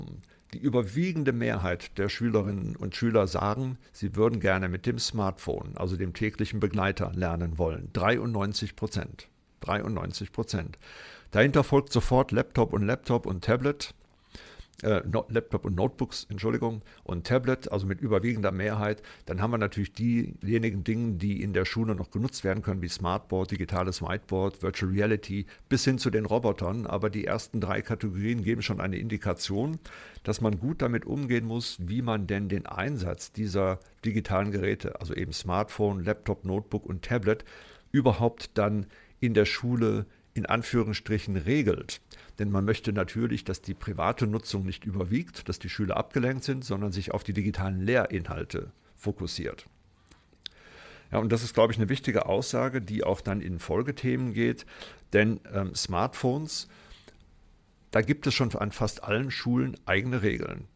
Mitschnitte der Pressekonferenz
bitkom-pressekonferenz-digitale-schule-2025-smartphones.mp3